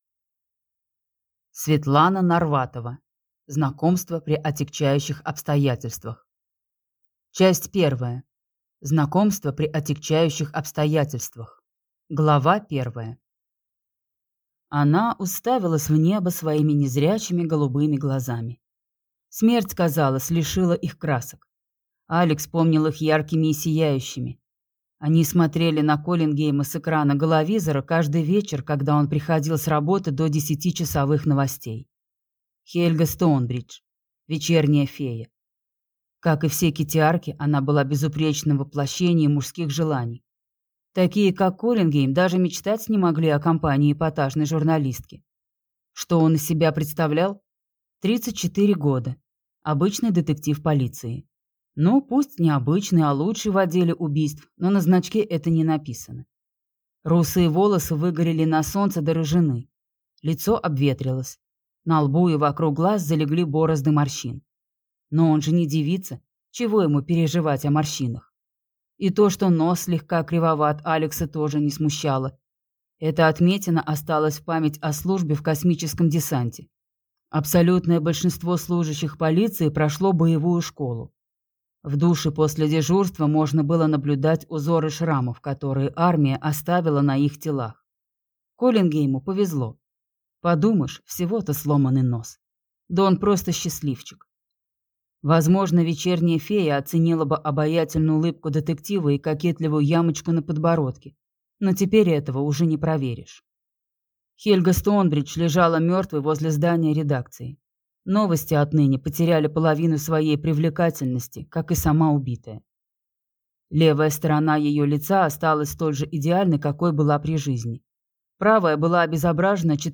Аудиокнига Знакомство при отягчающих обстоятельствах | Библиотека аудиокниг